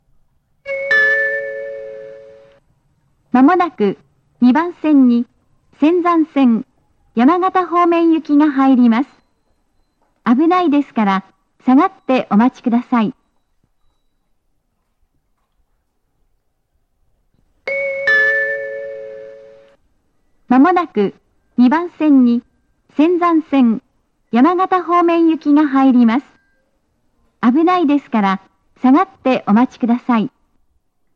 山形方面接近放送
●上りが男声、下りが女声の、路線名まで言ってくれる細かいアナウンスです。
●接近チャイムは仙台駅仙石線と同じタイプ。
●スピーカー：ユニペックス小型